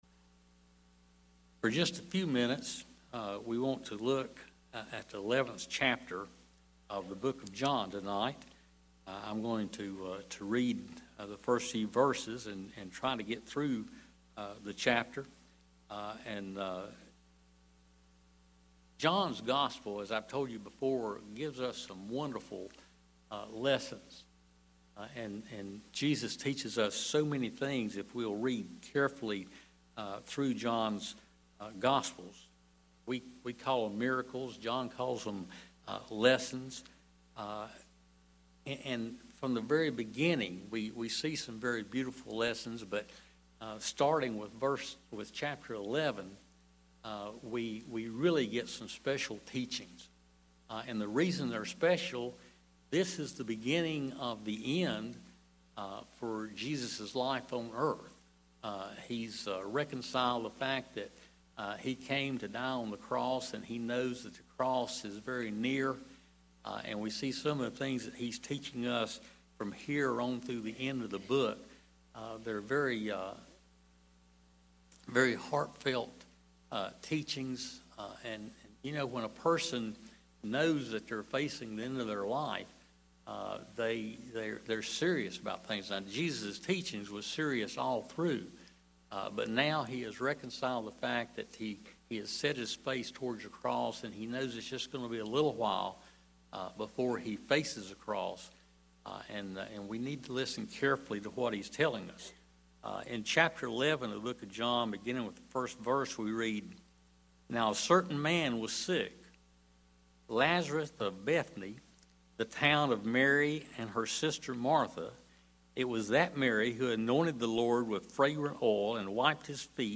Wednesday Prayer Mtg Passage: John 11 Service Type: Wednesday Prayer Meeting Share this